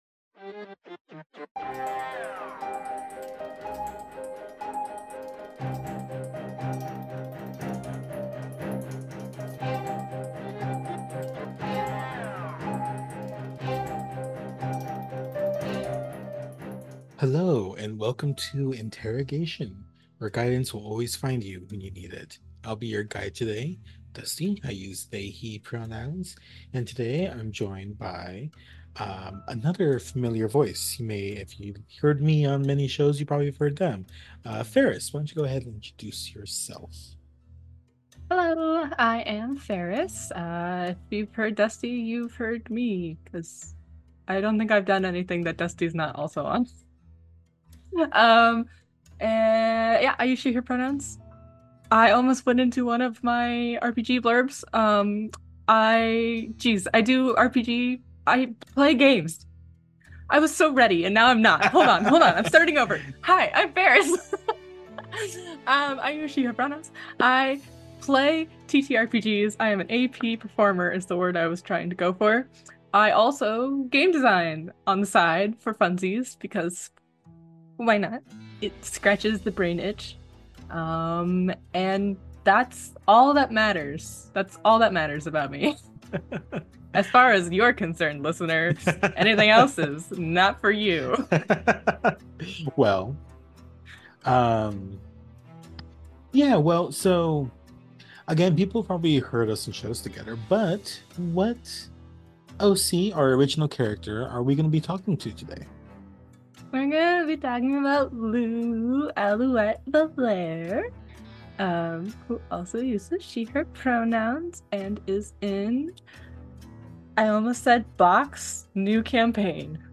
Intro and outro theme